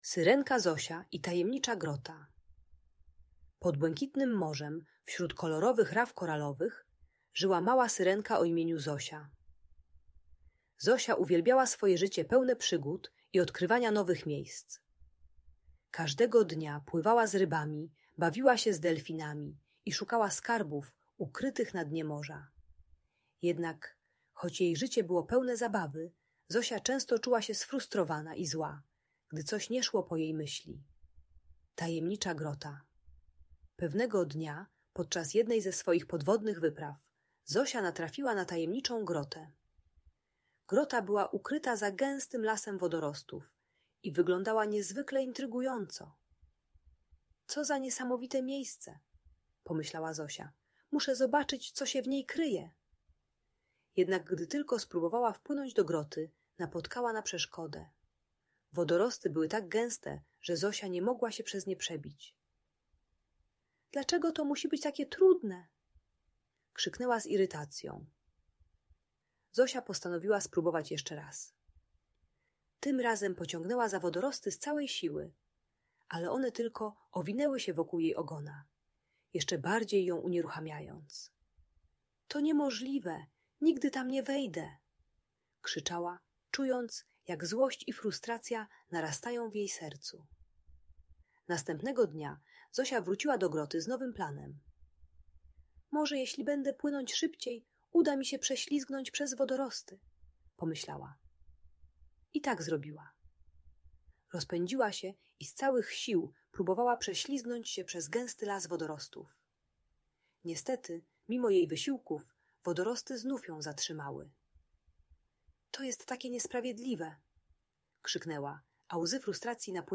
Przygody Syrenki Zosi - Opowieść o Tajemniczej Grocie - Audiobajka dla dzieci